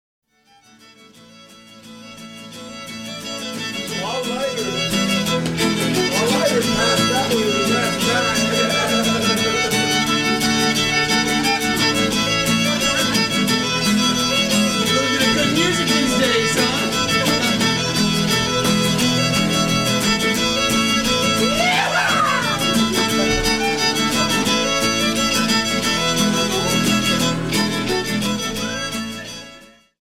lead vocal
keyboard, backup vocals
bass
drums